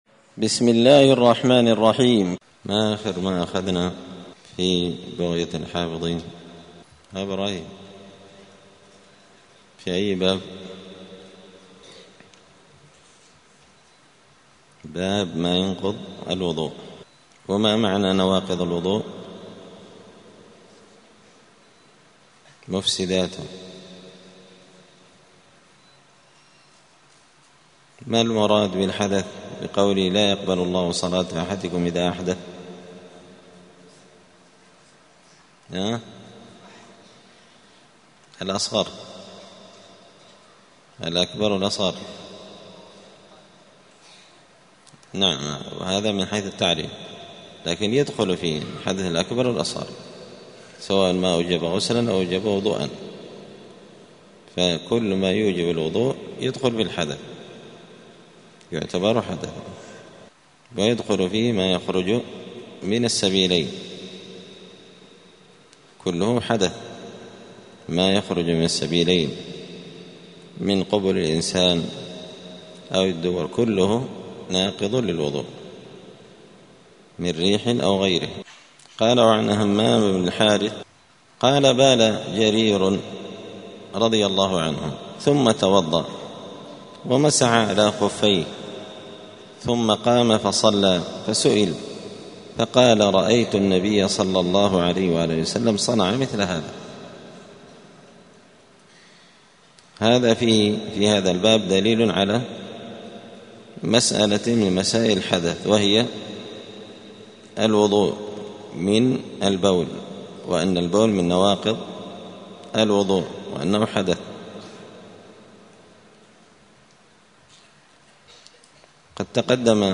دار الحديث السلفية بمسجد الفرقان قشن المهرة اليمن
*الدرس السابع والأربعون [47] {باب ما ينقض الوضوء البول والغائط ناقضان بالإجماع}*